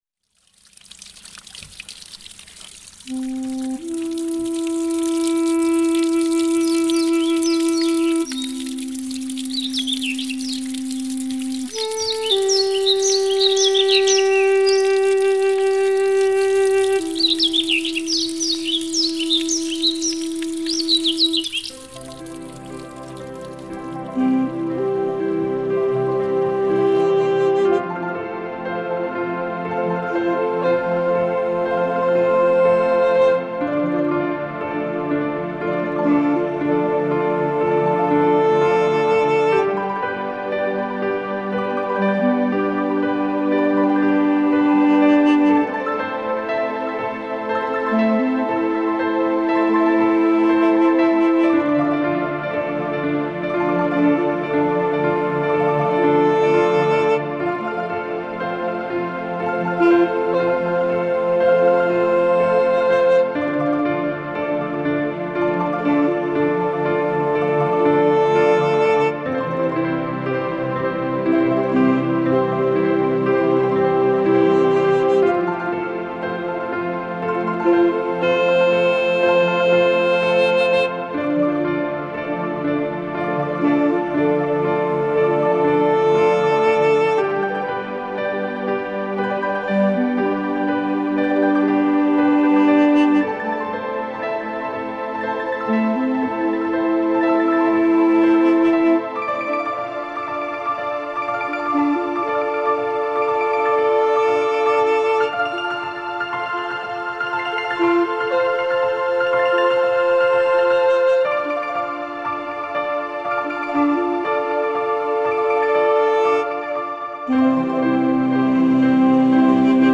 Новая  пропитывающая музыка